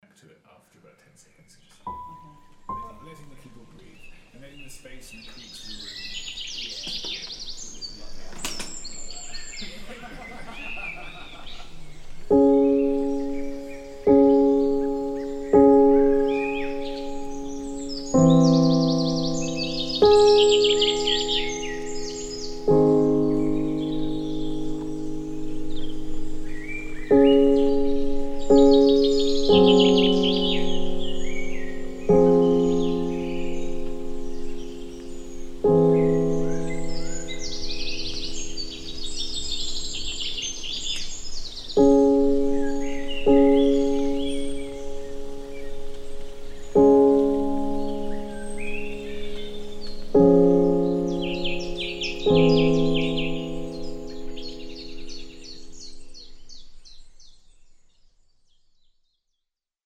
Soundscape
ambient soundtrack loop